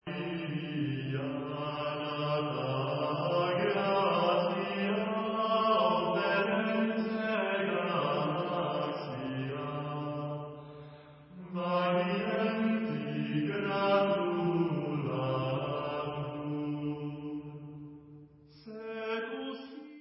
Leich